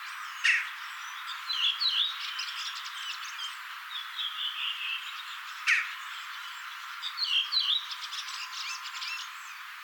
kaksi naakan ääntä
Joidenkin lintujet jotkin äänet ovat aika laadukkaita,
kaksi_naakan_aanta.mp3